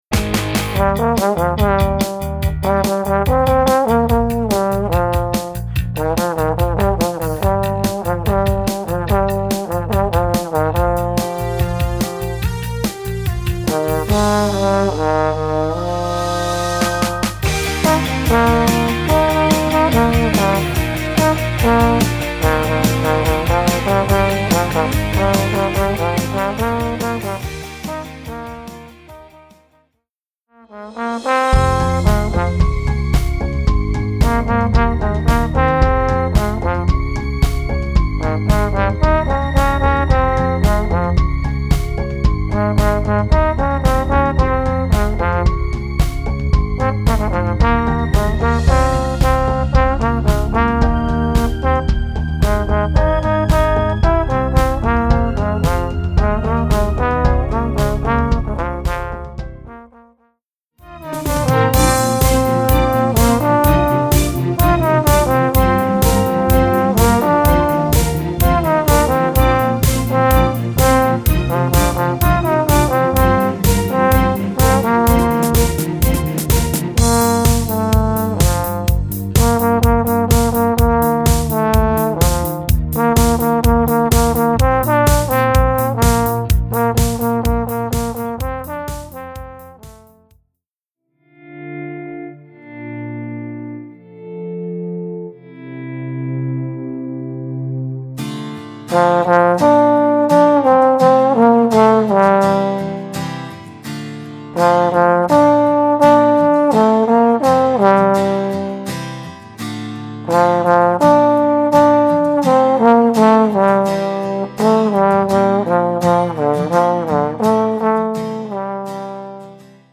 Voicing: Trombone w/ Audio